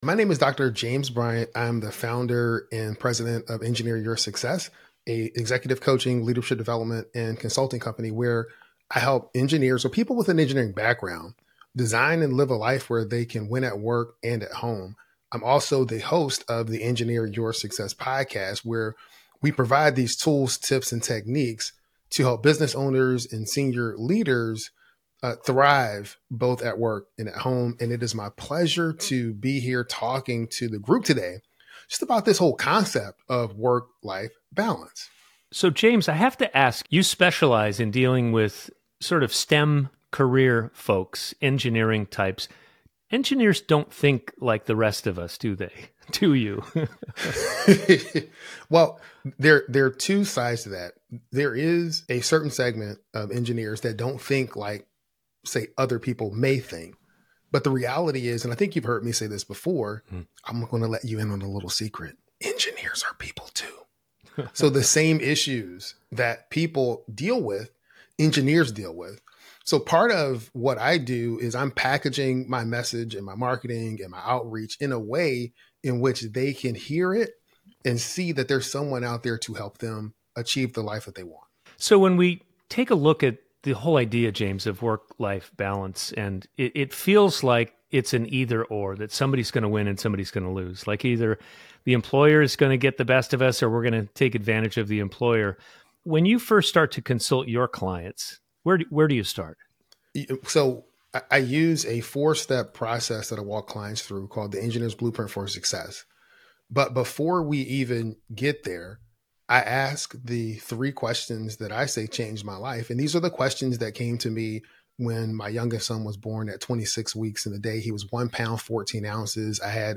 Join us for an insightful discussion on the essential role of managers and business owners in nurturing a supportive environment and balancing professional and personal success.